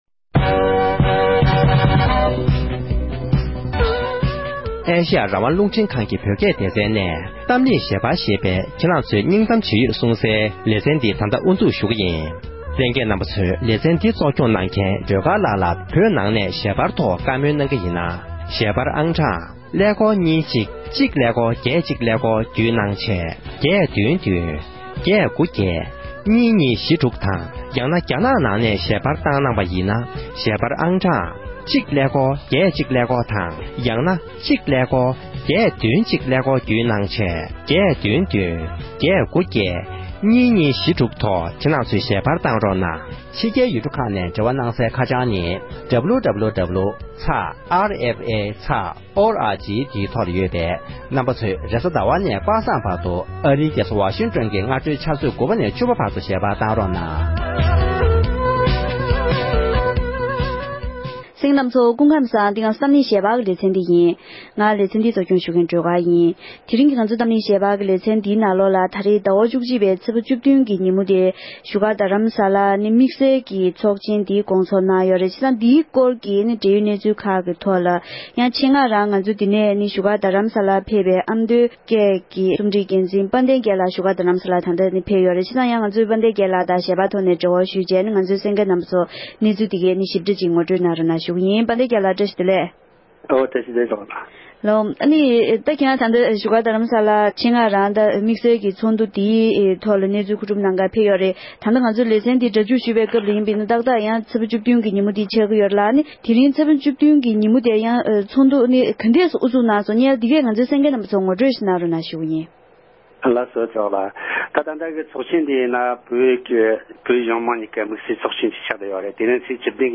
བཞུགས་སྒར་རྡ་རམ་ས་ལར་དམིགས་བསལ་ཚོགས་ཆེན་དབུ་འཛུགས་གནང་ཡོད་པའི་འབྲེལ་ཡོད་སྐོར་གྱི་བགྲོ་གླེང༌།